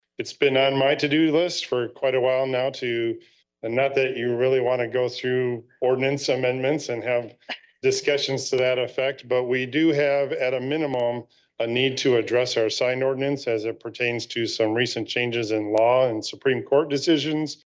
COLDWATER, MI (WTVB) – The Coldwater Planning Commission discussed possible changes regarding the city’s Zoning Ordinance for temporary and downtown signs during their Monday night meeting
City Manager Keith Baker told the Planning Commission they had been planning to do this after recent Supreme Court rulings.